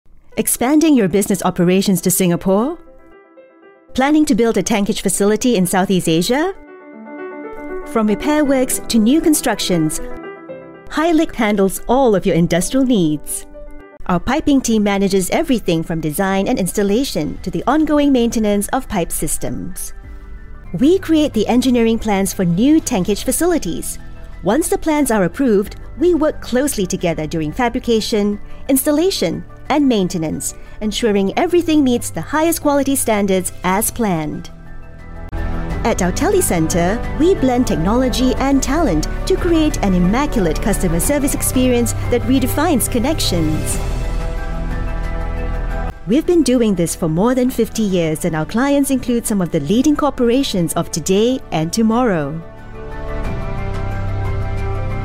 ~ Uma dubladora calorosa, amigável e versátil com apelo global ~
Microfone Rode NT1 (Kit de estúdio NT1 AI-1 com interface de áudio), suporte antichoque SM6, fones de ouvido Shure SRH440A Gen 2, filtro pop, escudo de isolamento, manta de amortecimento de som e placas de feltro